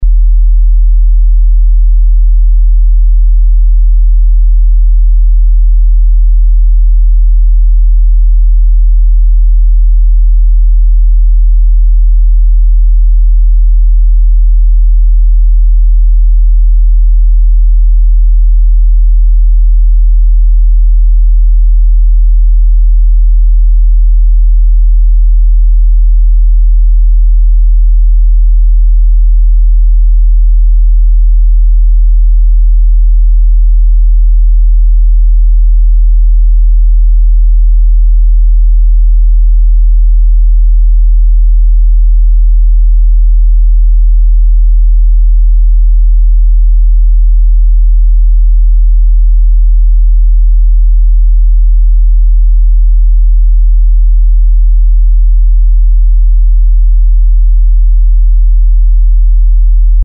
Where can i get a 40 hz test tone??
40Hz.mp3